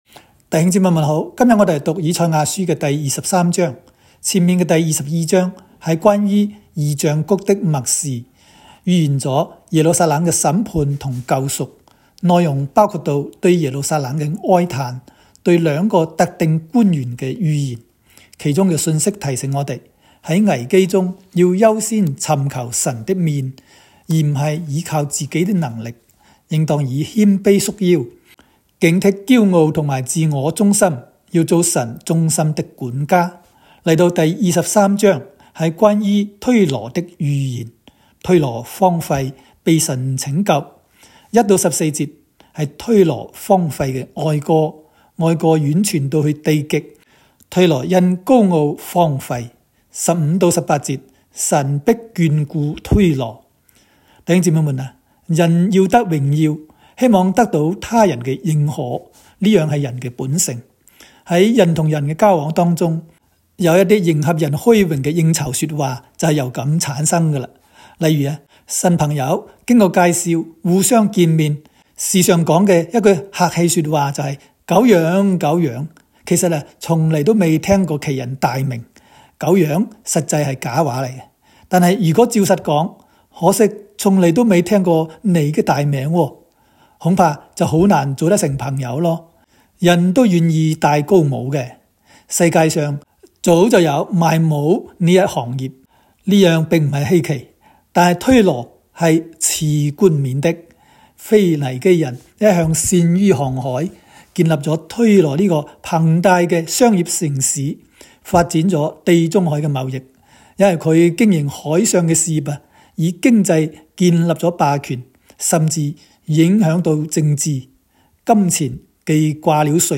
赛23（讲解-粤）.m4a